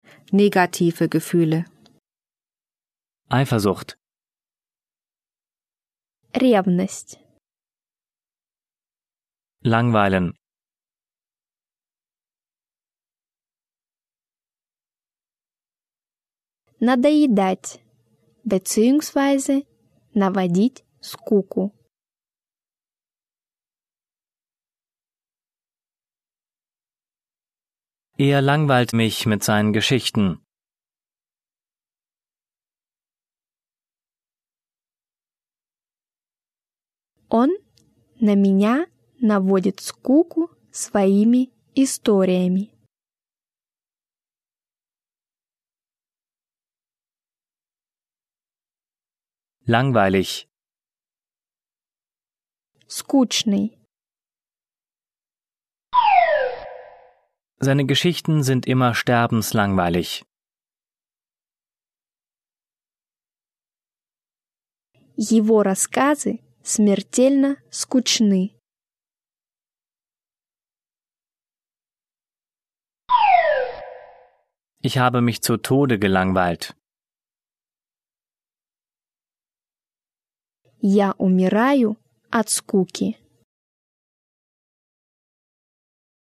Übersetzungs- und Nachsprechpausen sorgen für die Selbstkontrolle.